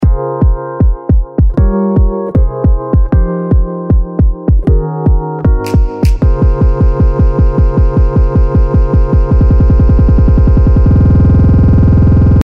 左端の「ROLL」は、スネア等を連打して盛り上げるロールができるエフェクトで、ON-OFFボタンを押したタイミングでリピートが開始されます。
ノブを右に回していくとRATEが1/8、1/16、1/32と細かくなっていきます。